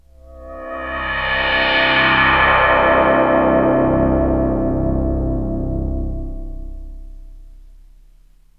AMBIENT ATMOSPHERES-1 0001.wav